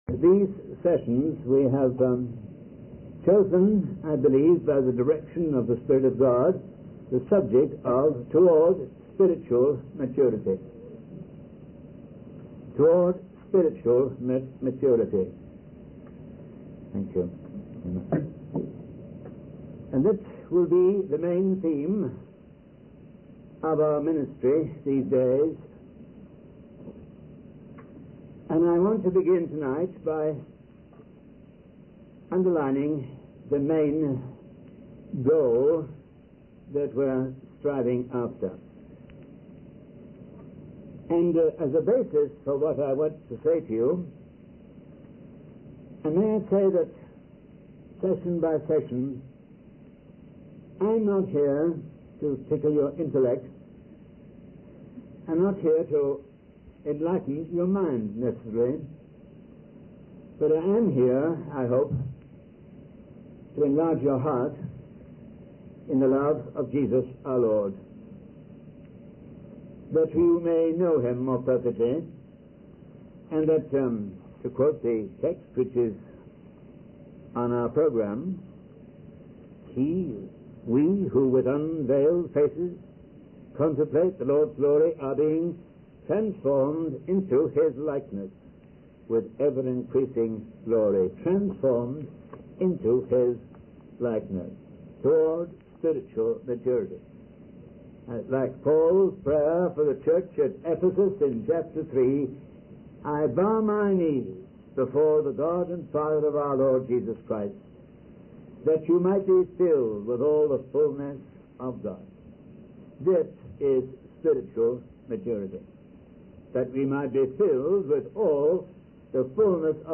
In this sermon, the speaker emphasizes the importance of waiting for God to intervene and perform miracles in our lives. He compares the audience to a mini pool of Bethesda, where people with different needs and struggles gather, all waiting for a miracle.